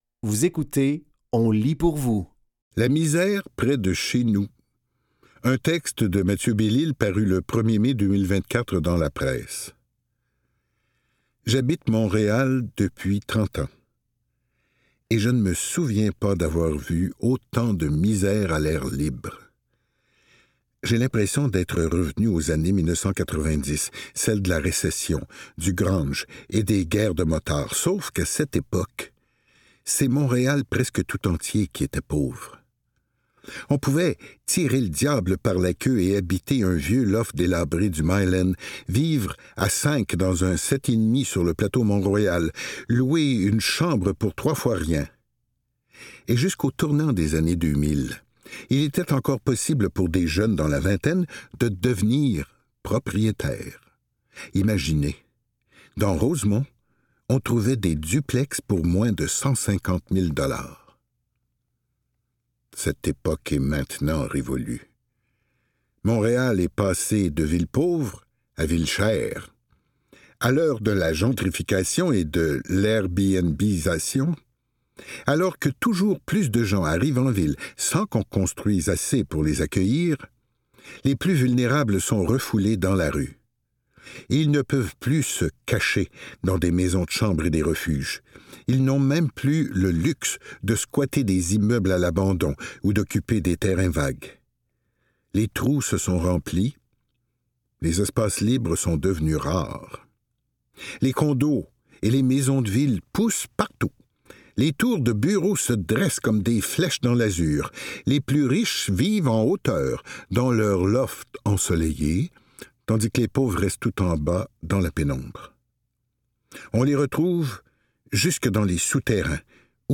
Dans cet épisode de On lit pour vous, nous vous offrons une sélection de textes tirés des médias suivants : La Presse, Le Devoir et ICI Grand Nord.